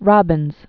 (rŏbĭnz)